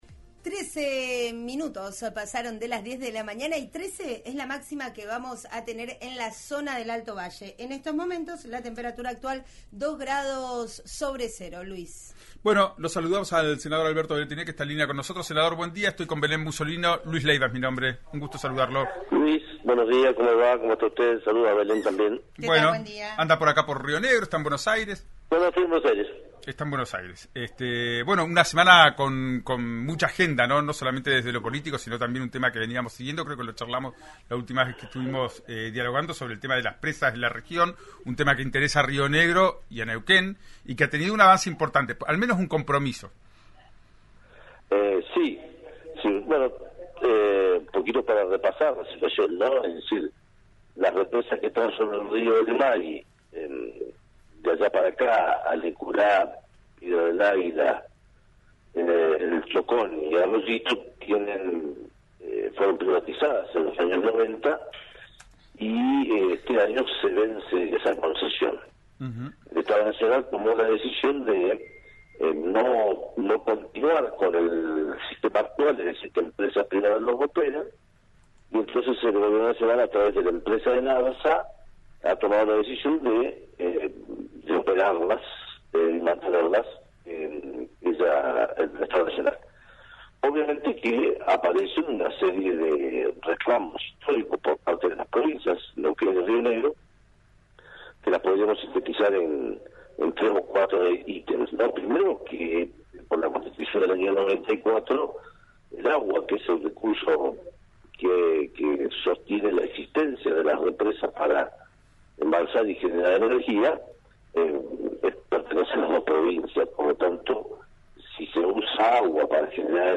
Fue el propio Weretilneck, que asumirá al frente del Ejecutivo rionegrino el próximo 10 de diciembre, quien contó a los micrófonos de RIO NEGRO RADIO, que repasaron algunos temas que tienen que ver con la generación de empleo, con los planes de vivienda y con la ayuda de Nación para asistir a las familias tras los temporales en la cordillera.